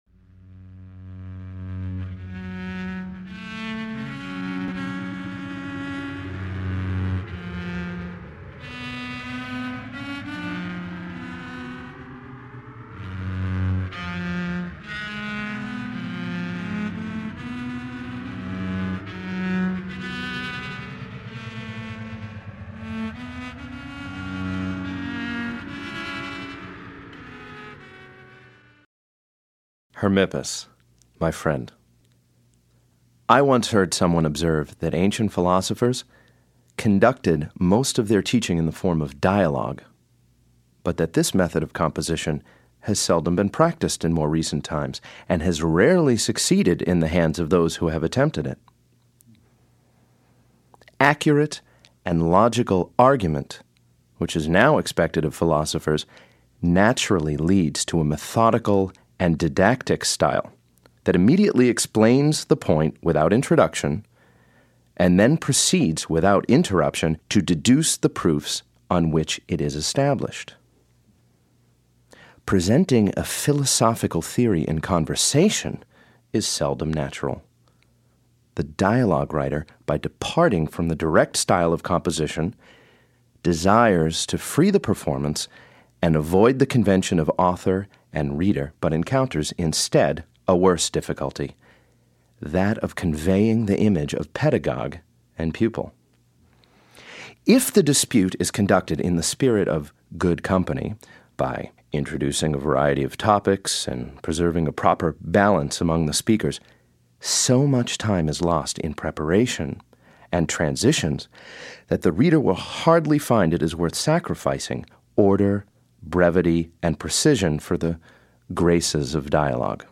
Audio knihaHume’s Dialogues Concerning Natural Religion (EN)
Ukázka z knihy